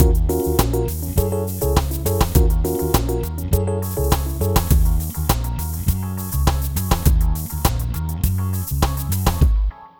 Ala Brzl 1 Fnky Full-D#.wav